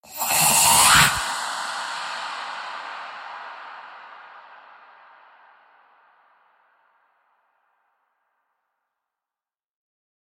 Звуки призраков
Призрак быстро пролетел
prizrak_bistro_proletel_6mc.mp3